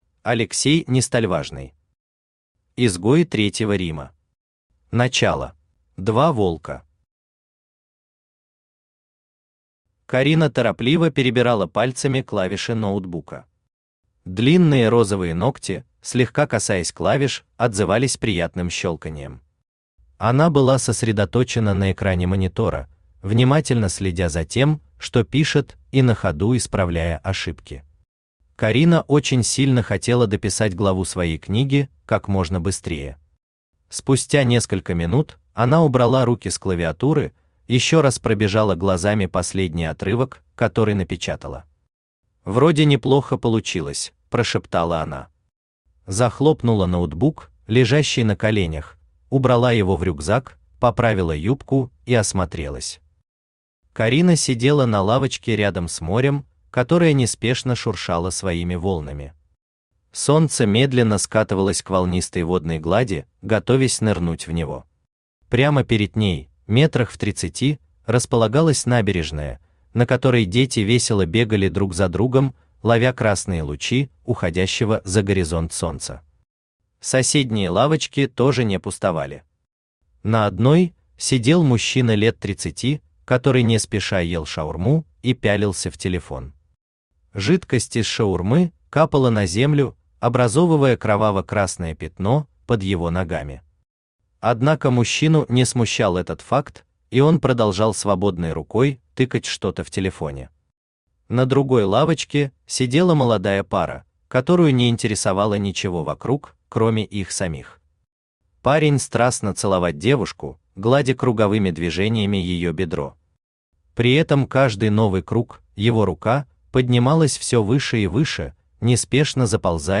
Аудиокнига Изгои Третьего Рима. Начало | Библиотека аудиокниг
Начало Автор Алексей Нестольважный Читает аудиокнигу Авточтец ЛитРес.